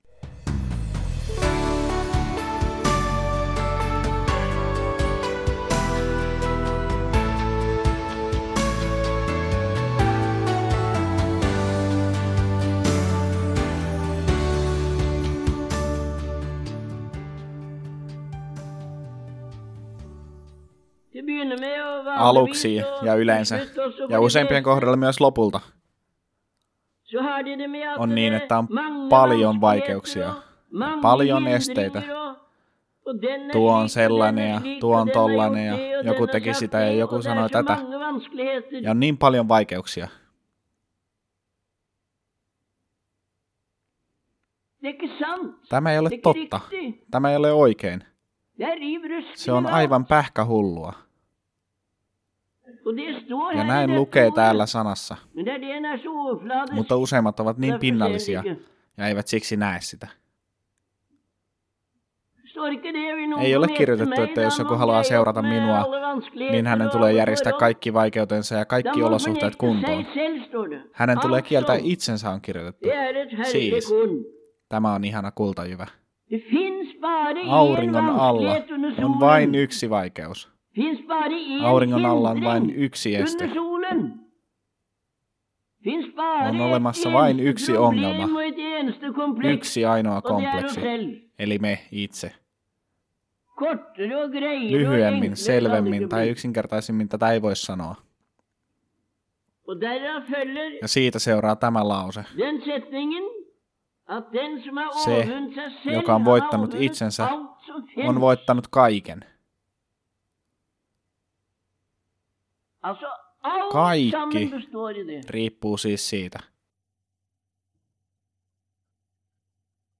Taleutdrag fra januar 1973